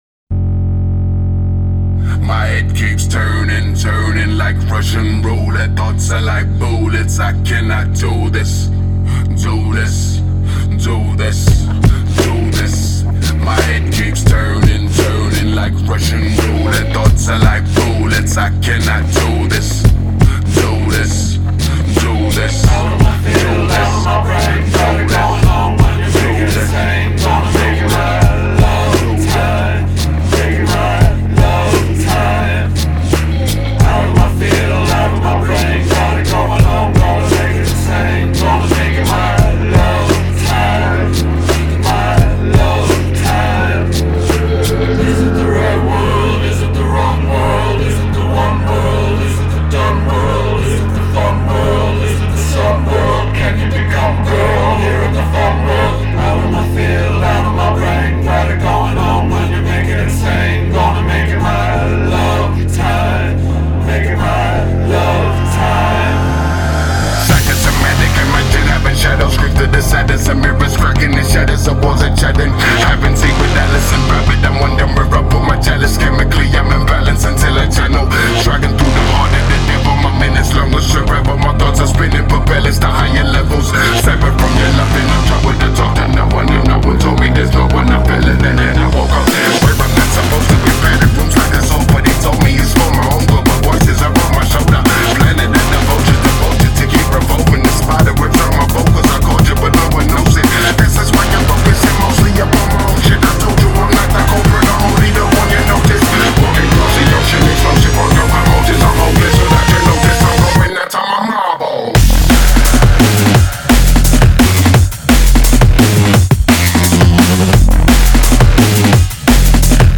Стиль музыки: D'n'B